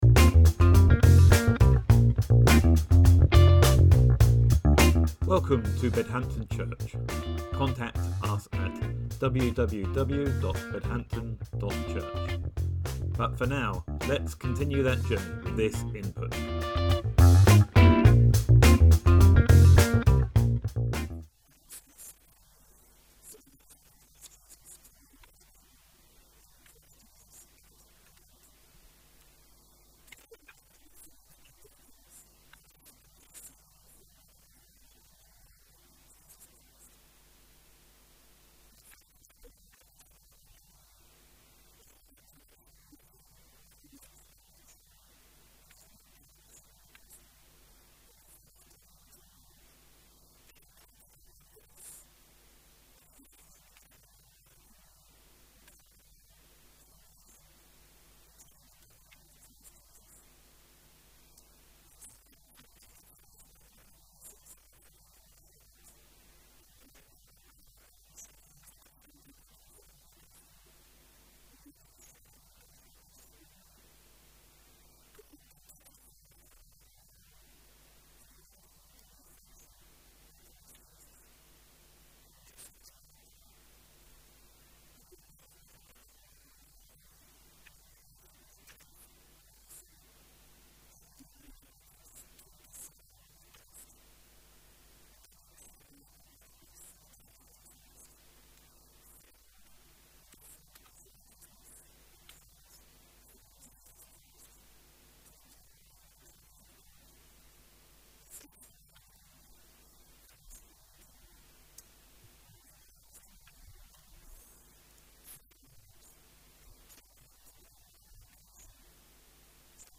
Sermon December 8th, 2024 – Second Sunday of Advent